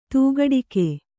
♪ tūgaḍike